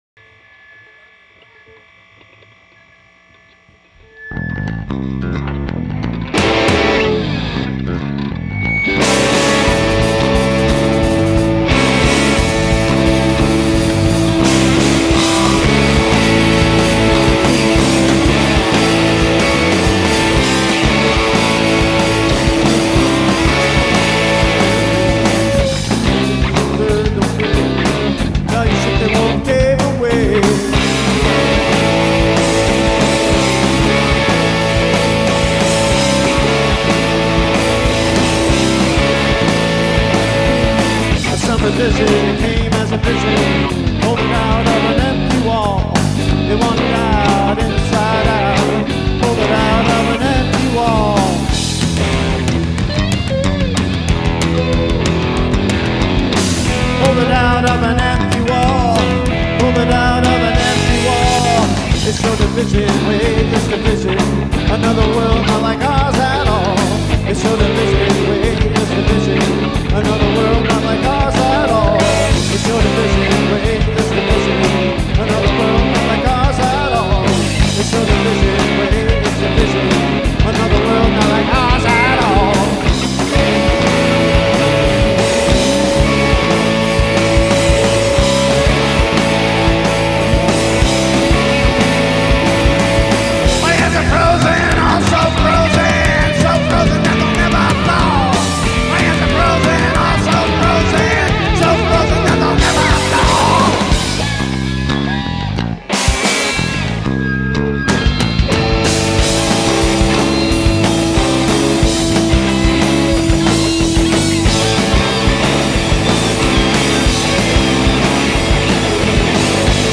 reunion show from 2006
bass
guitar
drums